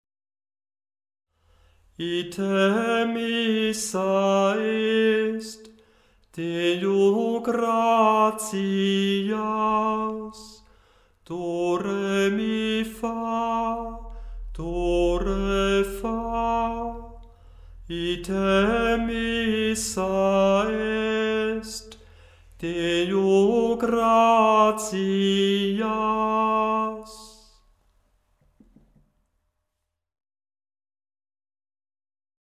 Die Gesänge sind in der Tonhöhe absteigend geordnet, zuerst deutsch, danach latein
ite-missa-est_gl-591-5_erster-ton-f1.mp3